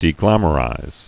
(dē-glămə-rīz)